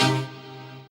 HOUSE120.wav